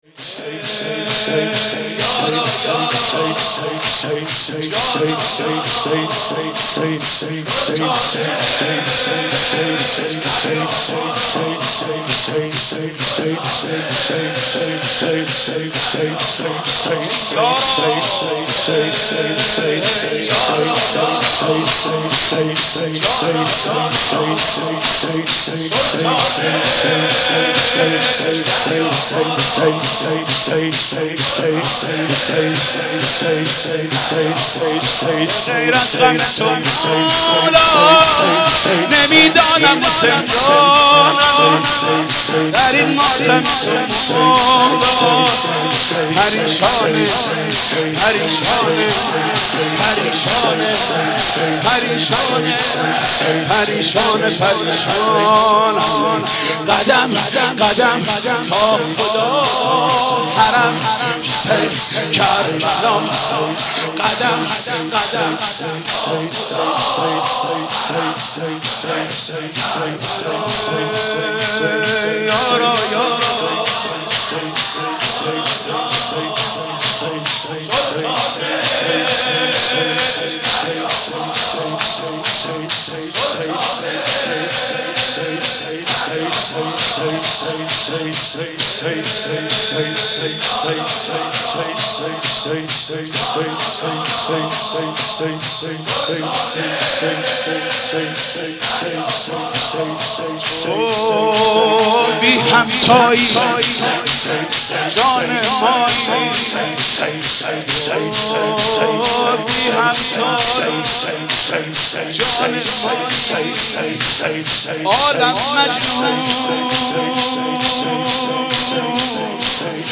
شور بیاد ماندنی